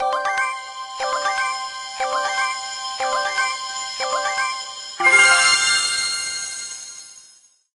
power_up_spawn_01.ogg